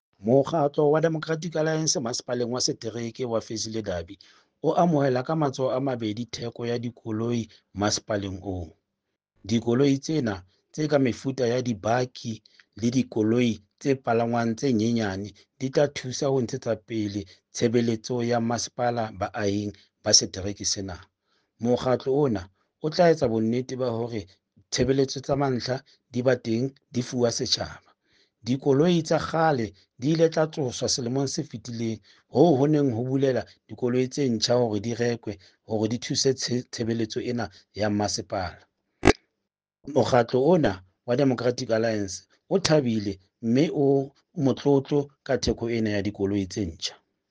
Sesotho soundbites by Cllr Stone Makhema and Afrikaans soundbite by Cllr Teboho Thulo.